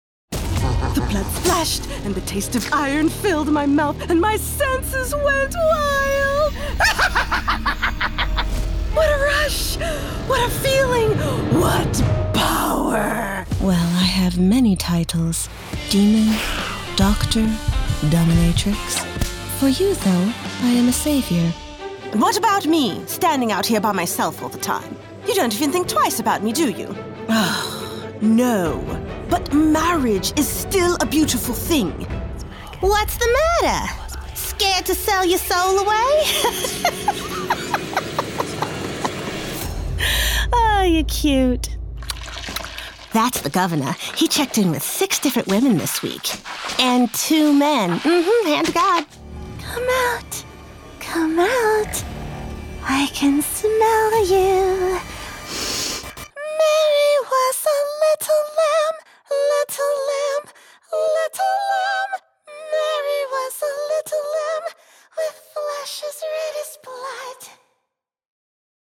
Character Demo
FRENCH, RP, COCKNEY, US REGIONAL